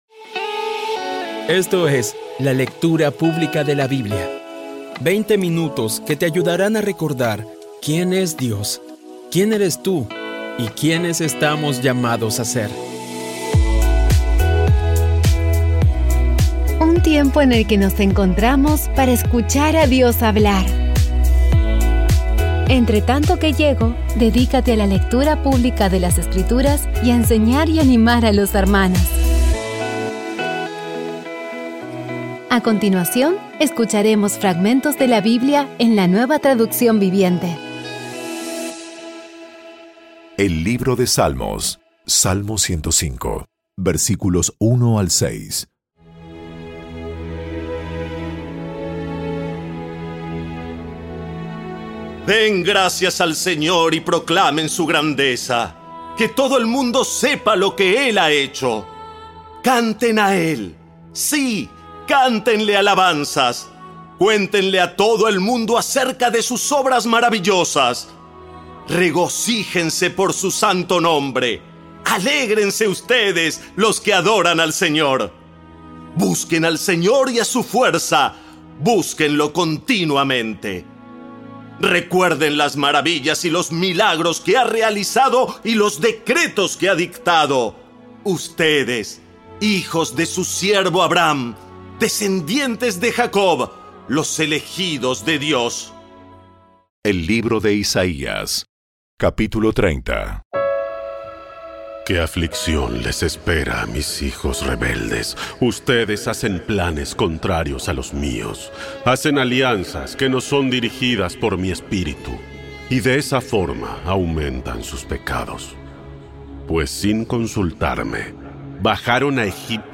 Audio Biblia Dramatizada Episodio 257
Poco a poco y con las maravillosas voces actuadas de los protagonistas vas degustando las palabras de esa guía que Dios nos dio.